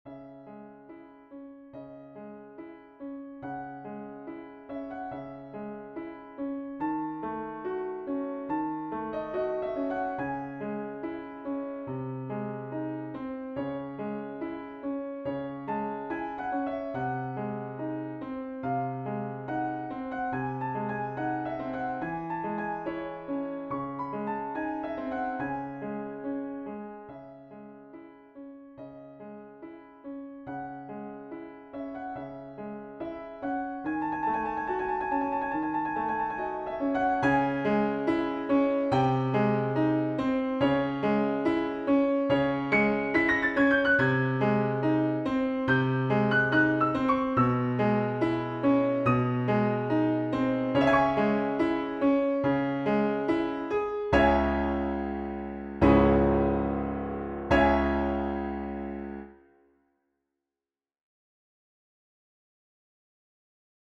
a nocturne in the style of chopin - Piano Music, Solo Keyboard - Young Composers Music Forum
a nocturne in the style of chopin